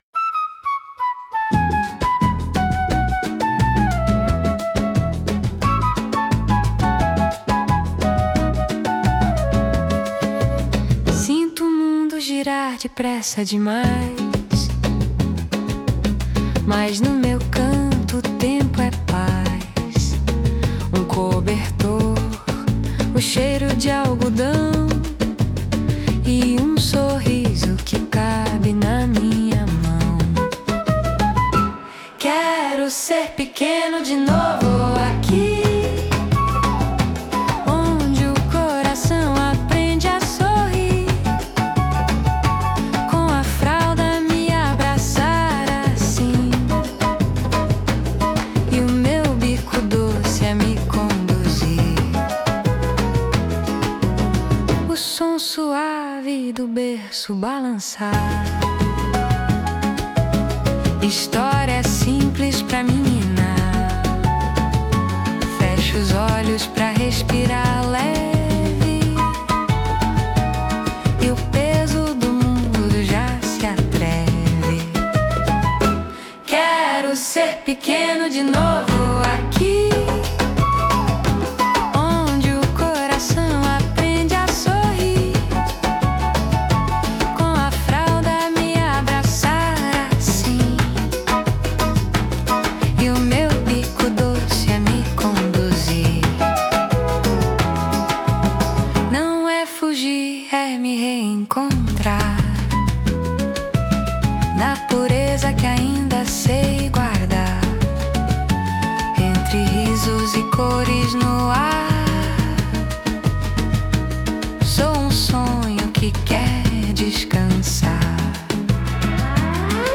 Genre: Samba